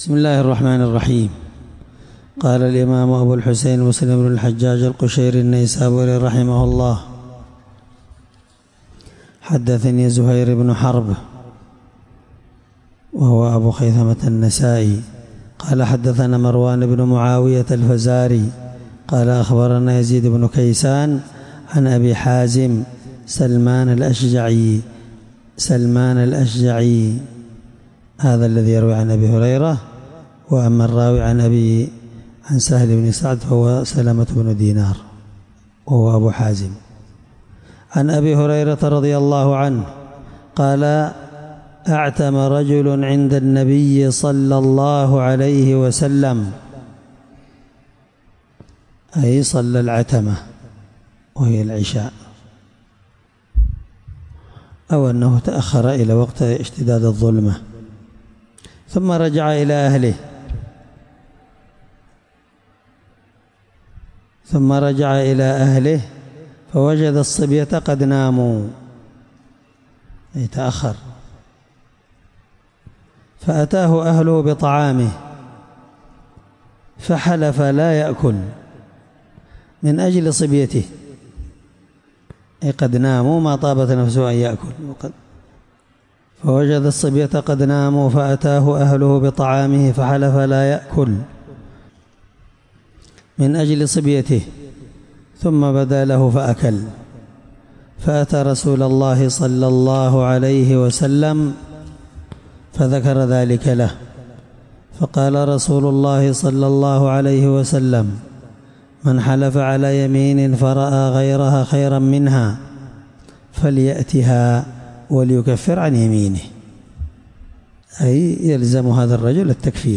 الدرس4من شرح كتاب الأيمان حديث رقم(1650) من صحيح مسلم